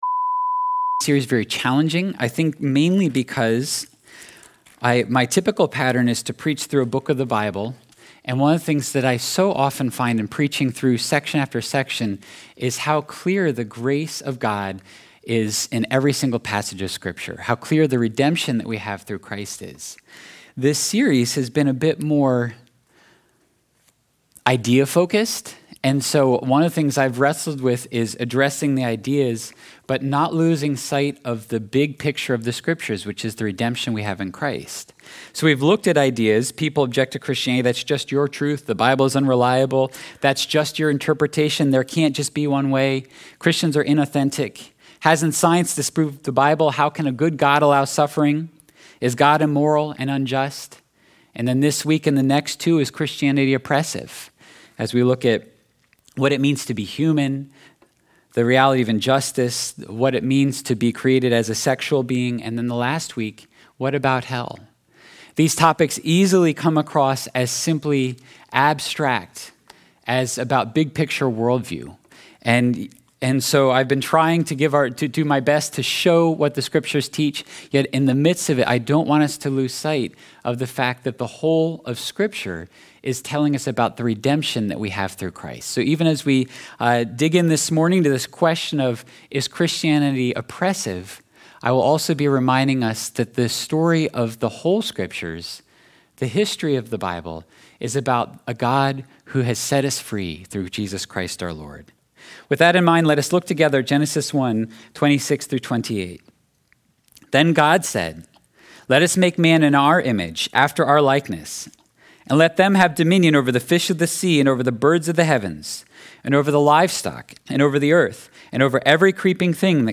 7.3.22-sermon-audio.mp3